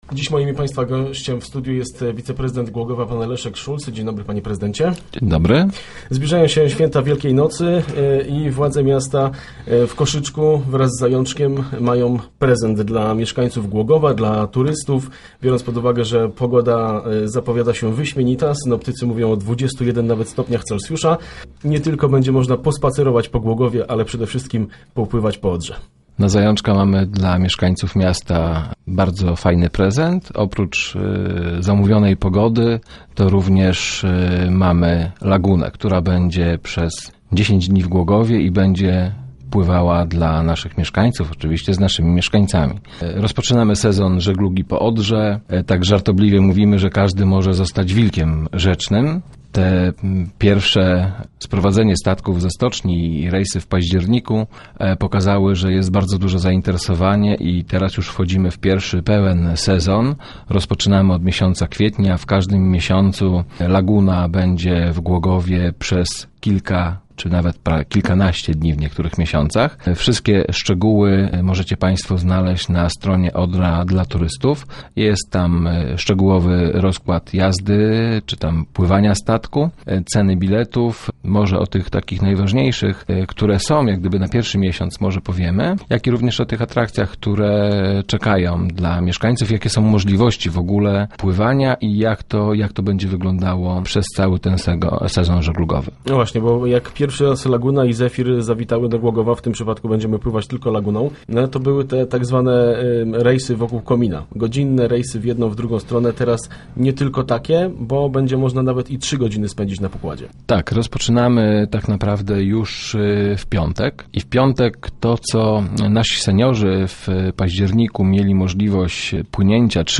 Wiceprezydent miasta Leszek Szulc był gościem środowych Rozmów Elki.